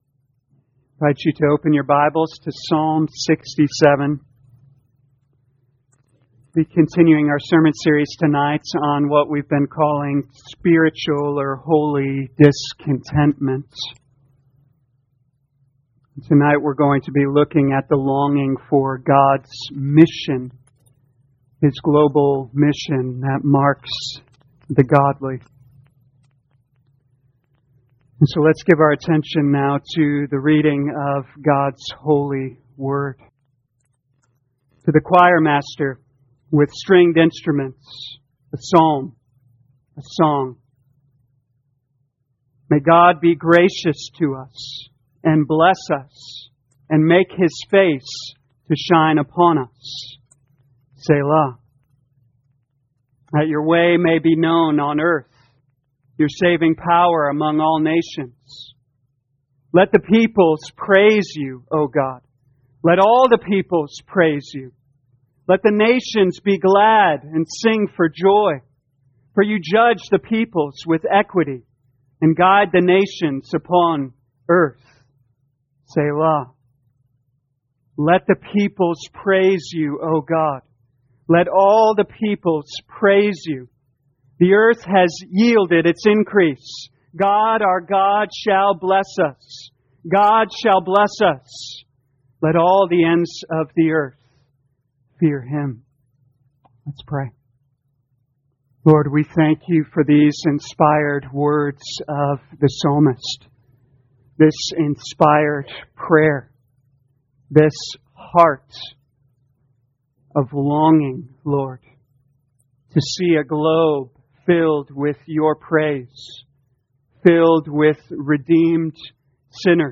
2021 Psalms Discontentment Evening Service Download